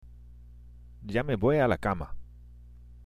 （ジャメボイ　アラカマ）